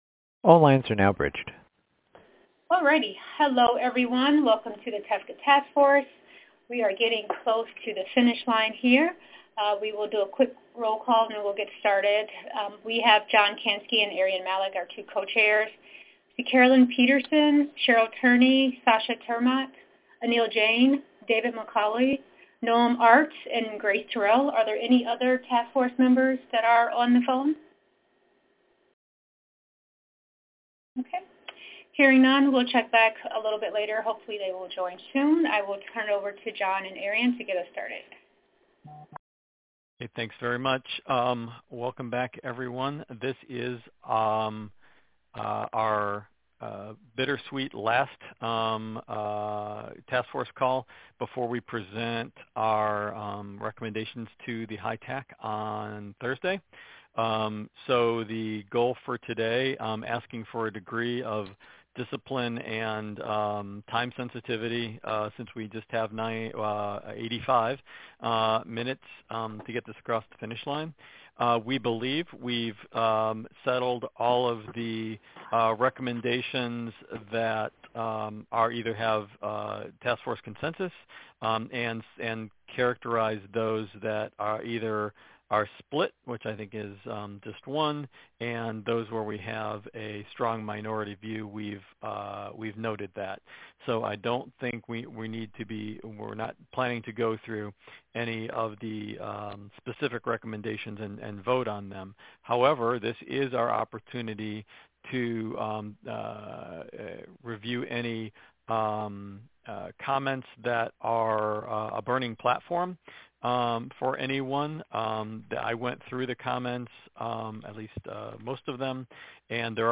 Trusted Exchange Framework and Common Agreement (TEFCA) Task Force Meeting Audio 7-9-2019